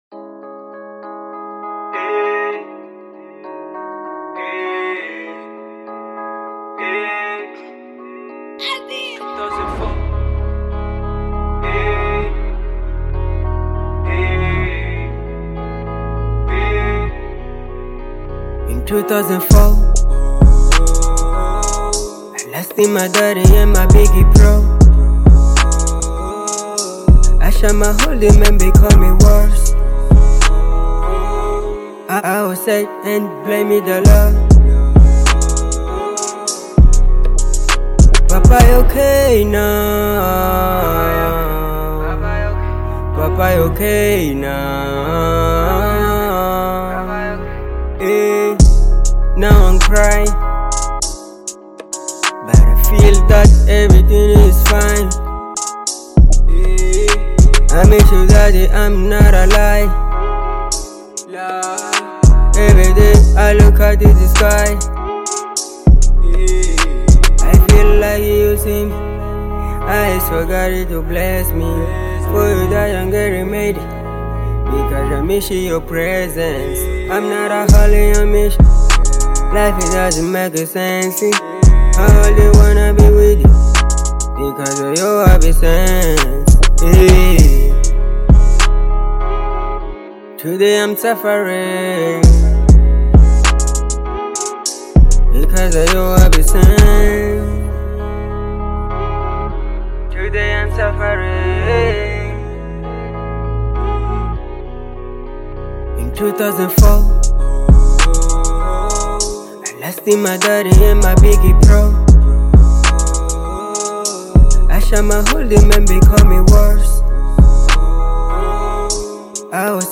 Genero: Trap